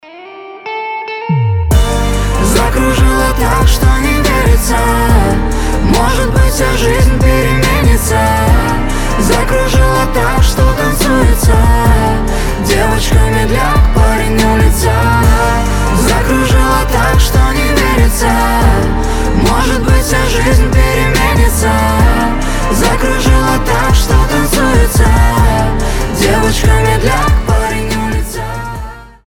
красивые
лирика
дуэт